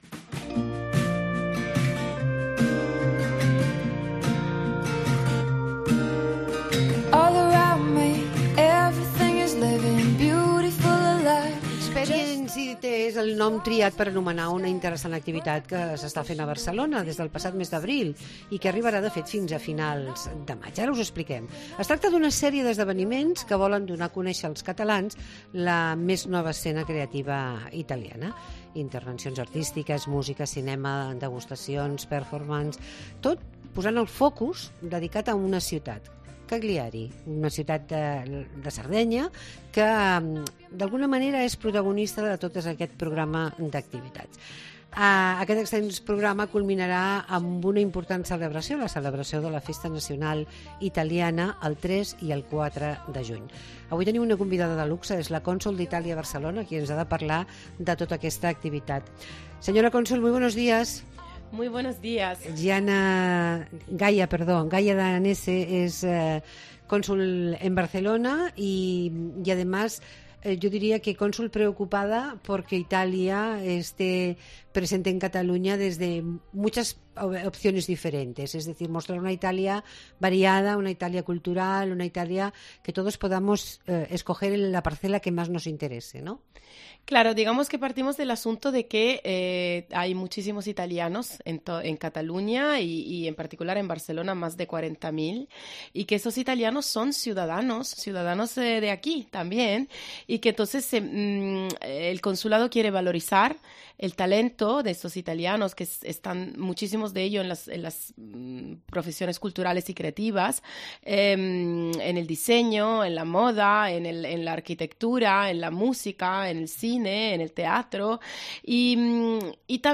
Entrevista a Gaia Danese, cònsul d'Itàlia